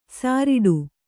♪ sāriḍu